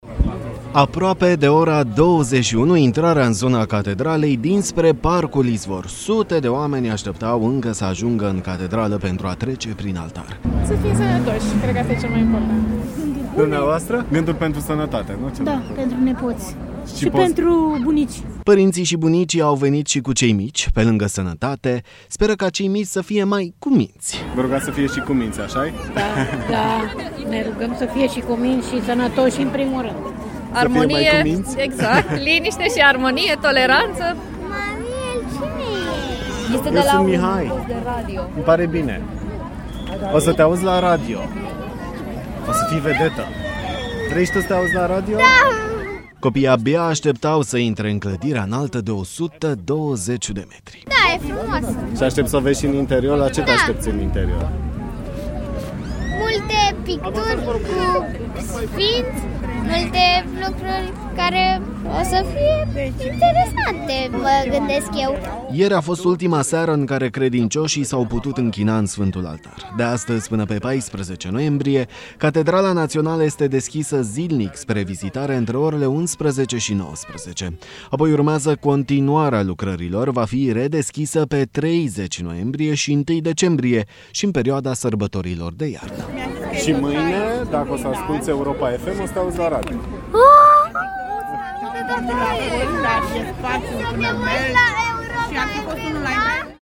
Știri > Social > REPORTAJ.
Aproape de ora 21:00. Intrarea în zona Catedralei dinspre parcul Izvor.
Credincioși de toate vârstele.